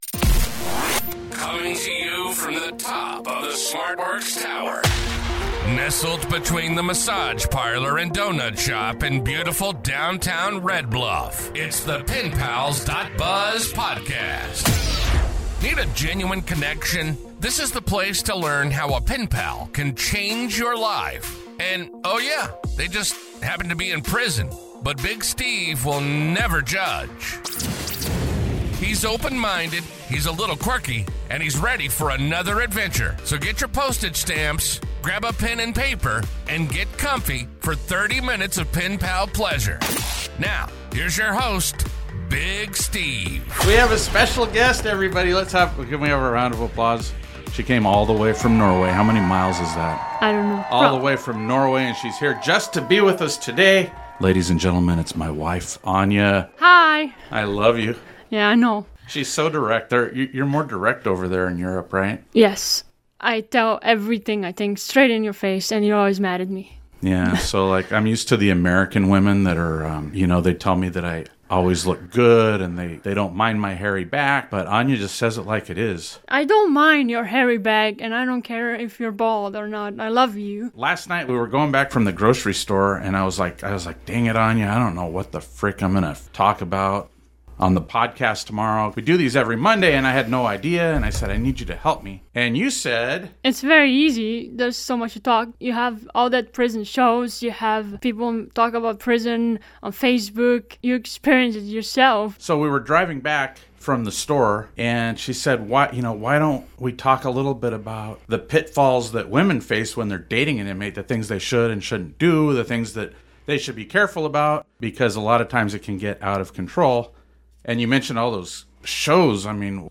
And enjoy a candid conversation, with lots of laughs, between this happily married husband and wife.